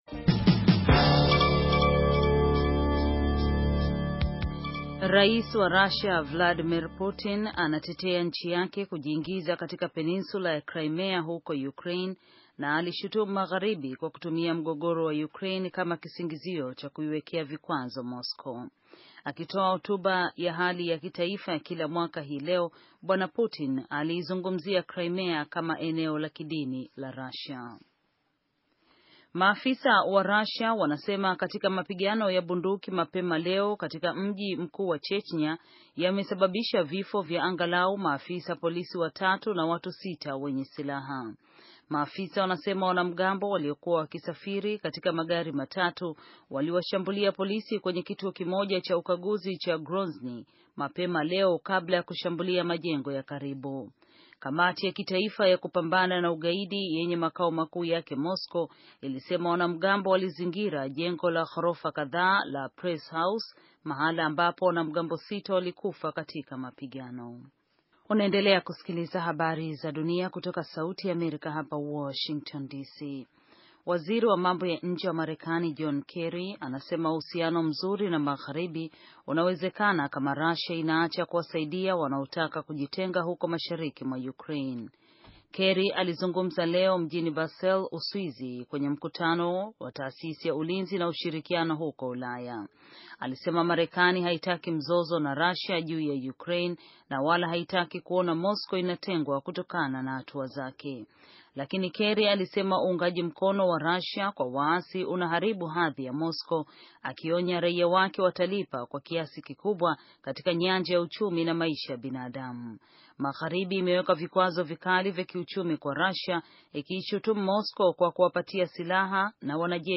Taarifa ya habari - 6:18